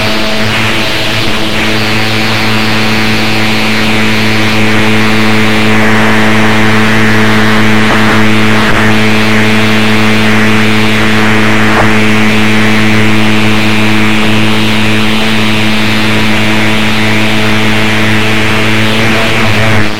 “Speak Thinking” – thinking and silently speaking what you think captured by Gillette.
ultrasound speaking through the blades gillette power fusion